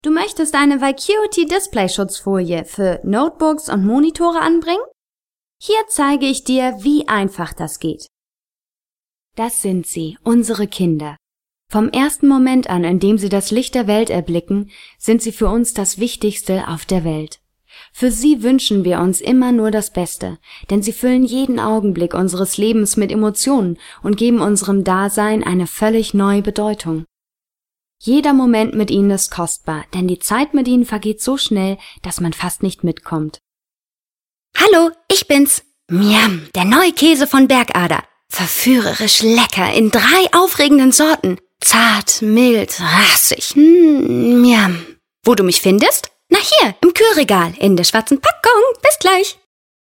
Sprechprobe: Sonstiges (Muttersprache):
Fresh, young, warm, rich in variety, sensual or funny, quiet, gentle and charming voice.